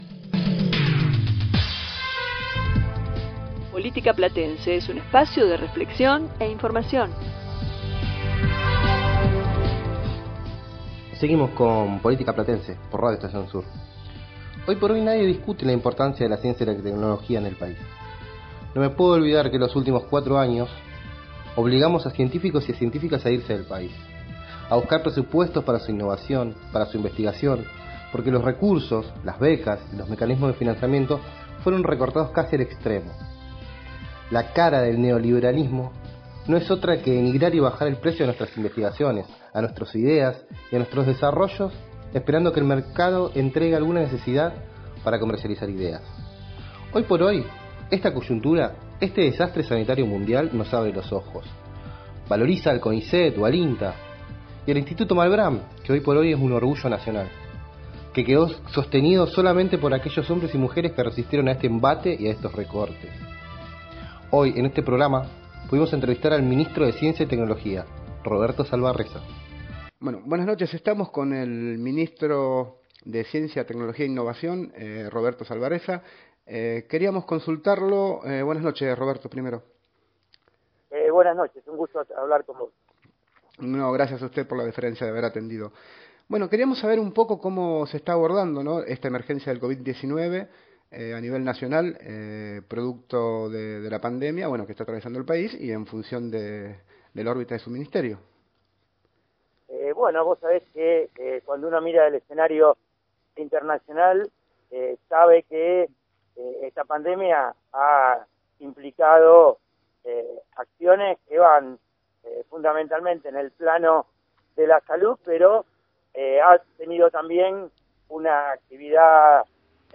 Entrevista con Roberto Salvarezza, Ministro de Ciencia, Tecnología e Innovación
B4-Entrevista-Salvarezza.mp3